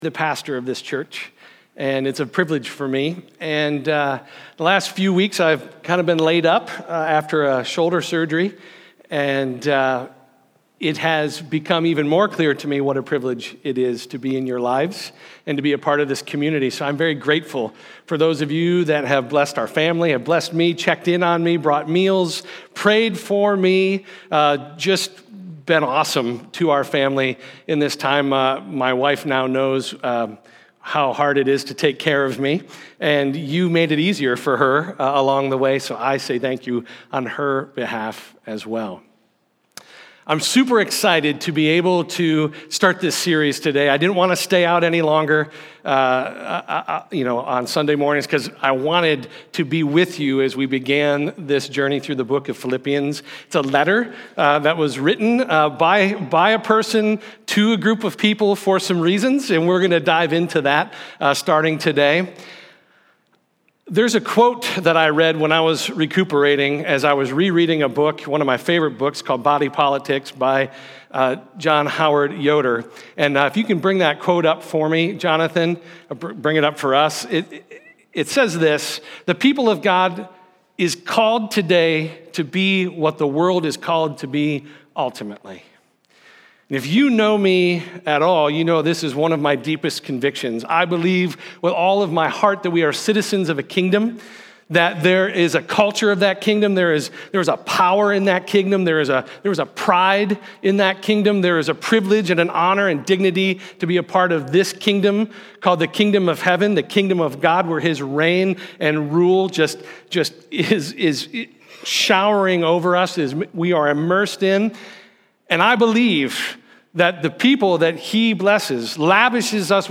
Sermons
2-9-20_5_stones_sermon.mp3